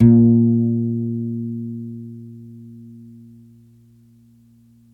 GUITARON 03L.wav